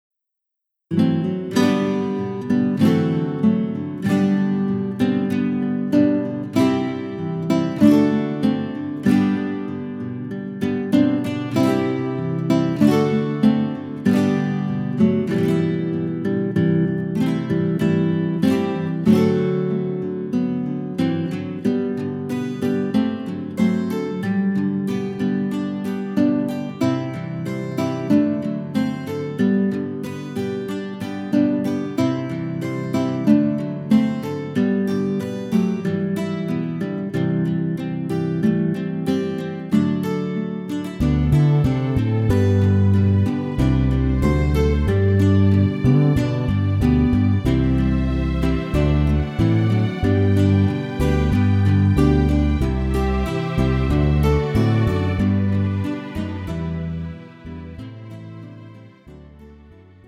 음정 -1키 3:14
장르 가요 구분 Pro MR
Pro MR은 공연, 축가, 전문 커버 등에 적합한 고음질 반주입니다.